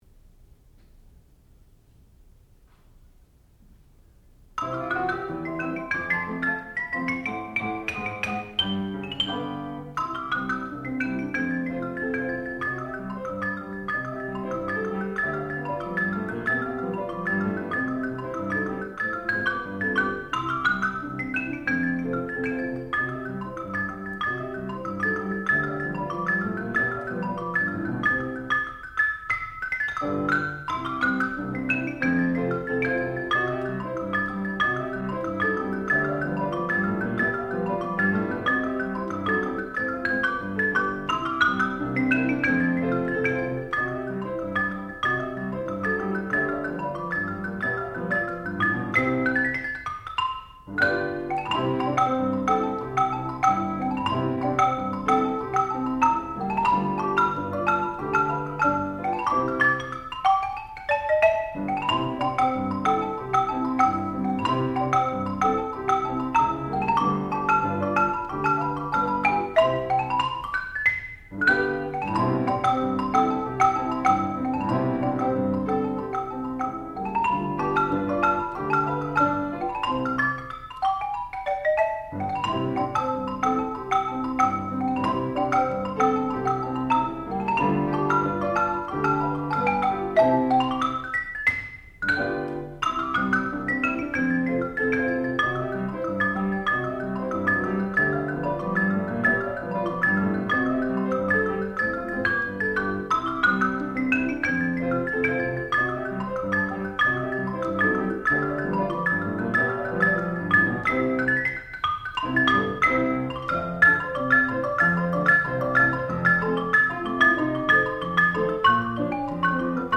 sound recording-musical
classical music
percussion
piano
Junior Recital